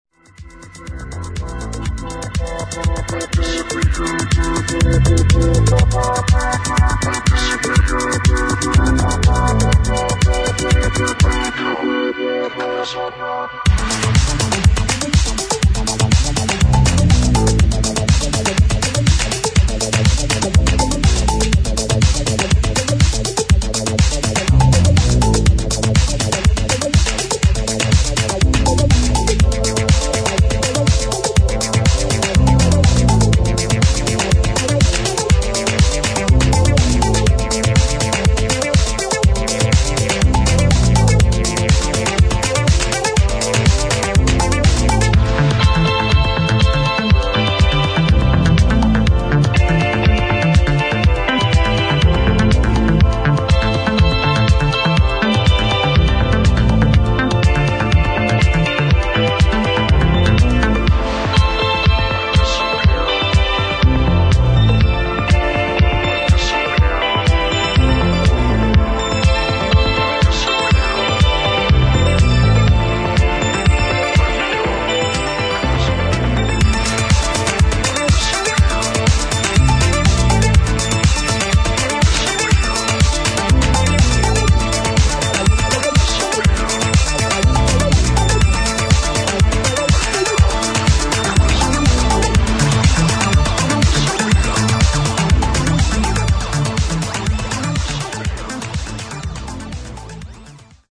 [ HOUSE / ELECTRONIC ]